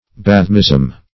Bathmism \Bath"mism\, n.